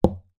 Звуки дротиков
Дротик вонзается в дартс-доску